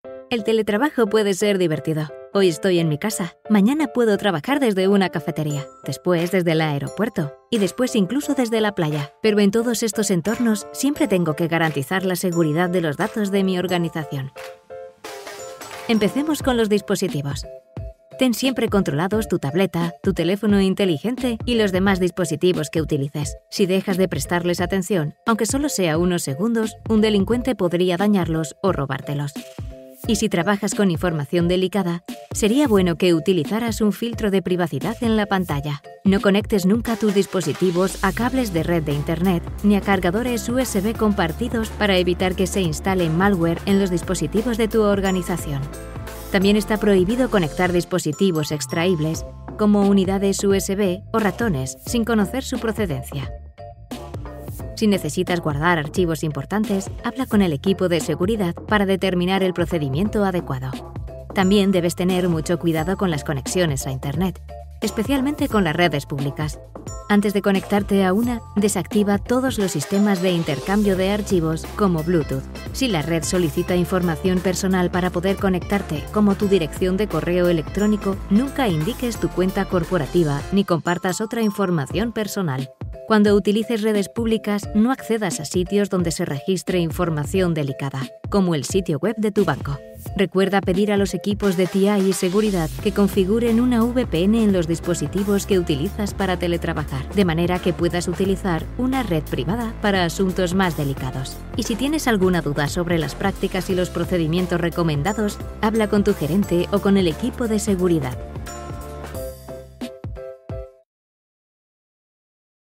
Commercial, Accessible, Versatile, Warm, Soft
Explainer